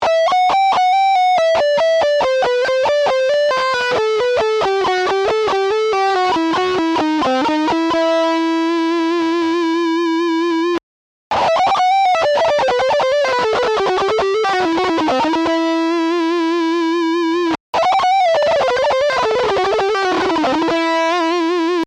Example 1 - the exercise below uses a scale sequence that combines regular picking with hammer ons and pull offs.
Guitar Scale Sequence Tab